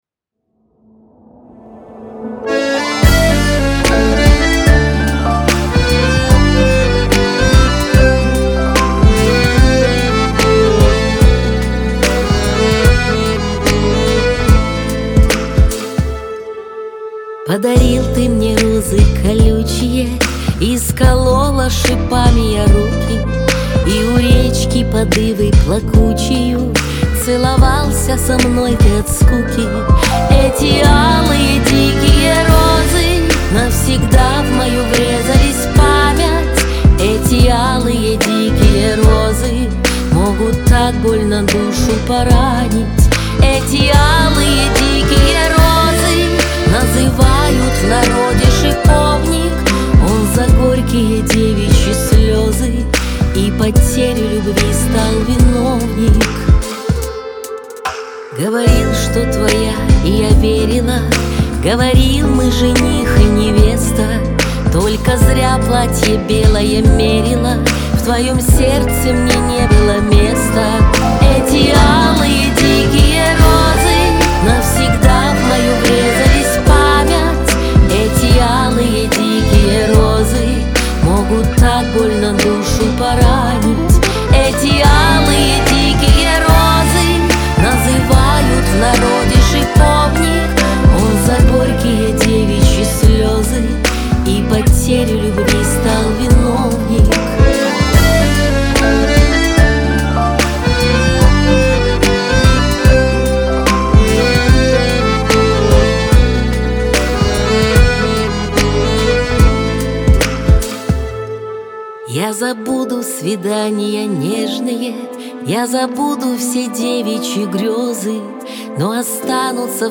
Шансон
Лирика
грусть